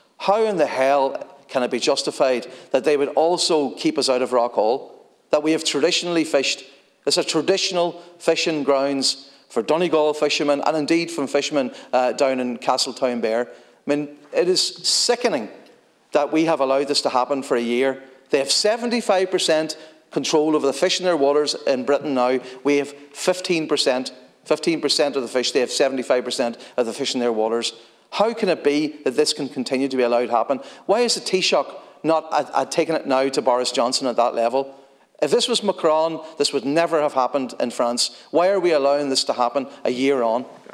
Speaking in the Dail, Donegal Deputy Padraig MacLochlainn says its unacceptable that the issue has been allowed to drag on: